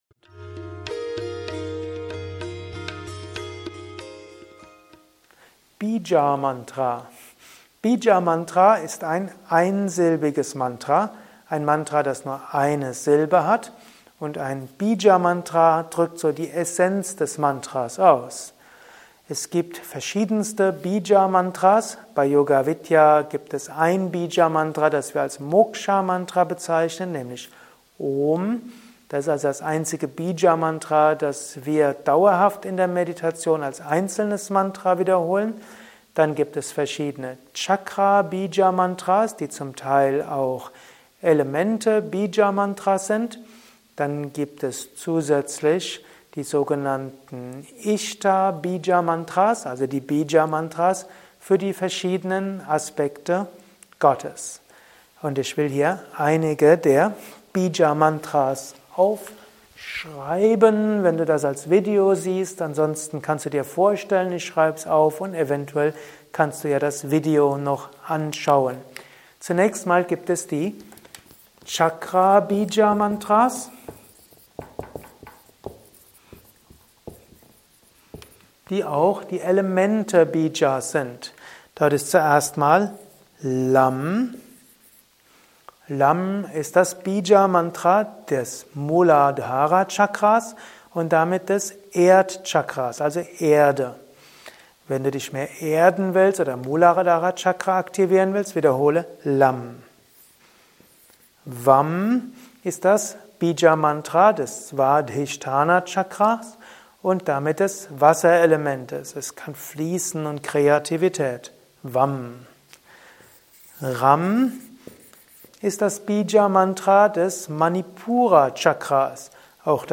Mantra-Meditation-Podcast ist die Tonspur eines Mantra Videos.